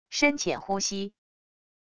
深浅呼吸wav音频